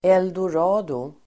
Ladda ner uttalet
Uttal: [eldor'a:do]